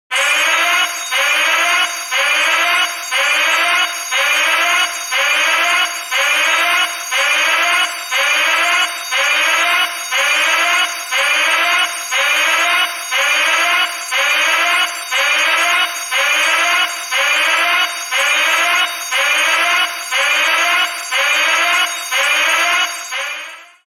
Emergency Alarm Sound FX (NO Sound Effects Free Download